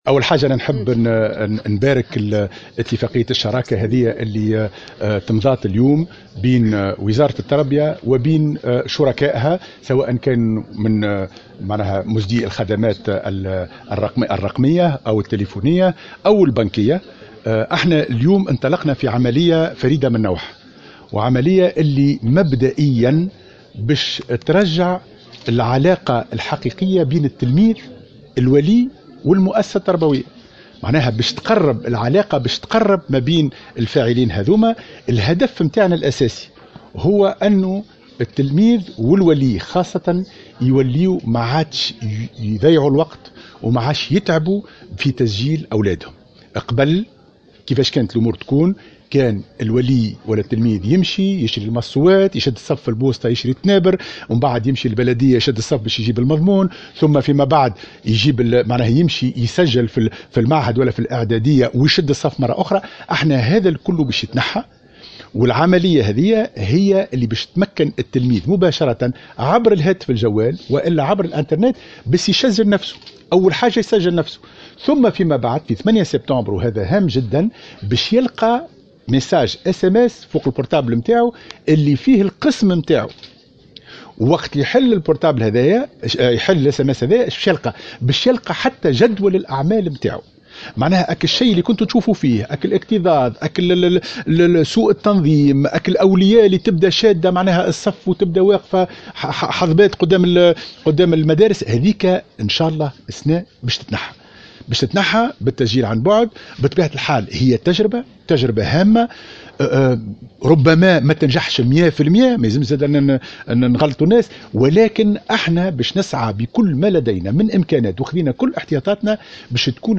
وأوضح في تصريح لمراسلة "الجوهرة أف أم" أن التلميذ سيتلقى يوم 8 سبتمبر القادم ارسالية قصيرة تتضمّن معلومات حول القسم الذي سيدرس به وجدول الأوقات الدراسي.